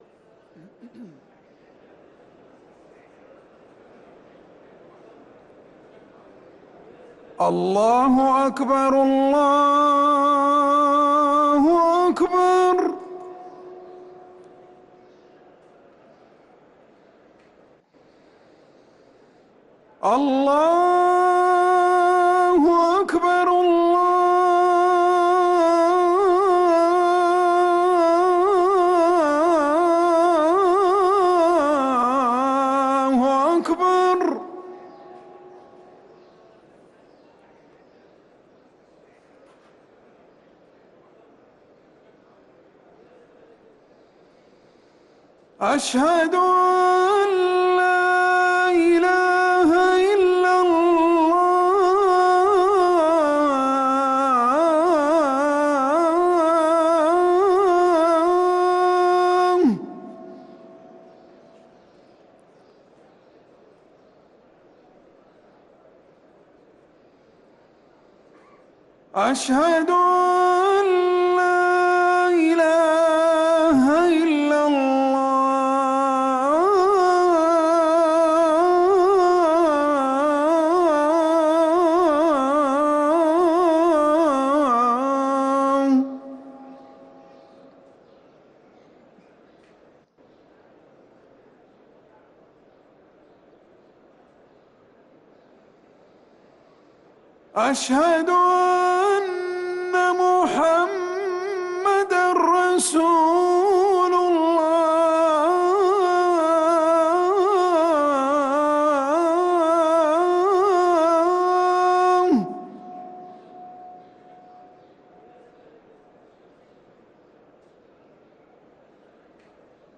أذان الجمعة الأول للمؤذن عمر سنبل 1 شوال 1444هـ > ١٤٤٤ 🕌 > ركن الأذان 🕌 > المزيد - تلاوات الحرمين